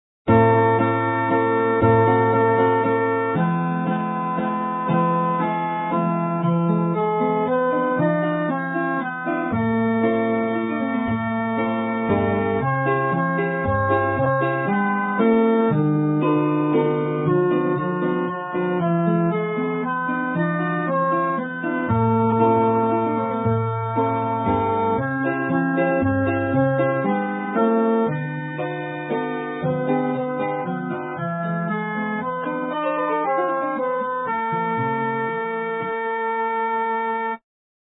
Как обычно, вместо голоса звучит флейта:
А пока — пока мы имеем то, что имеем: «Нiч» образца 1914 года в бандурной обработке Василия Шевченко.